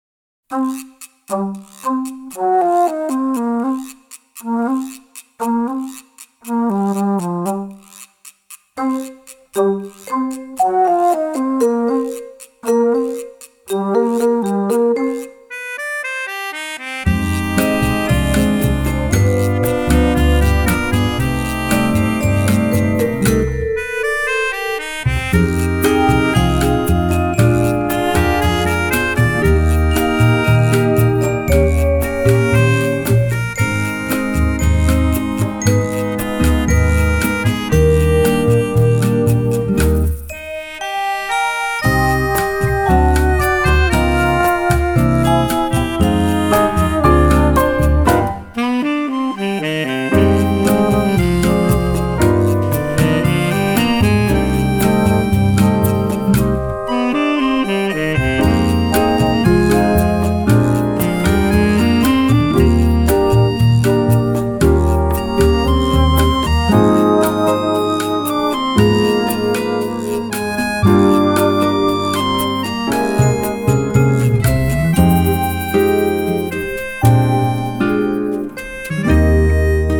★ 滿載拉丁熱情、爵士、探戈與古典齊聚一堂的音樂小小冒險！
音質清澈通透、密度超高！
長笛、拉丁樂器的巧妙運用，音樂更顯色彩繽紛、清新雋永
flute,vibrandoneon
saxophone
guitar,mandolin
piano,accordion
double bass
percussion,vibraphone,bells
violoncello